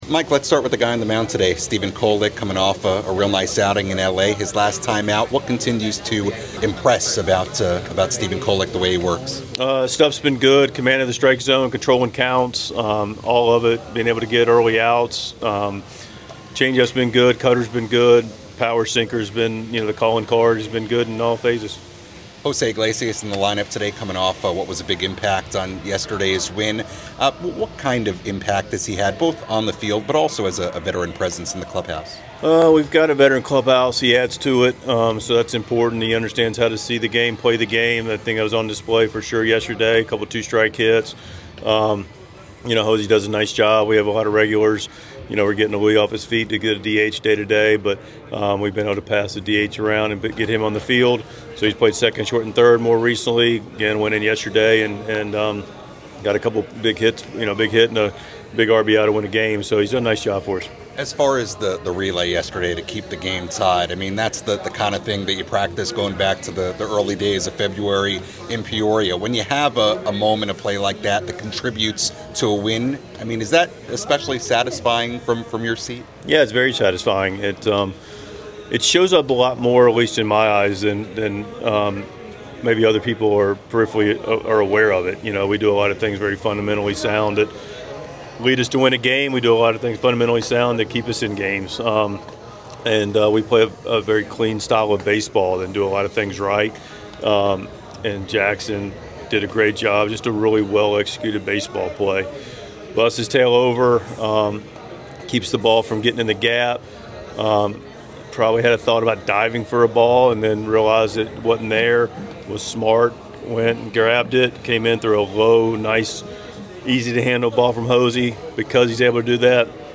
Padres manager Mike Shildt speaks with the media before the team's series opener against the Washington Nationals.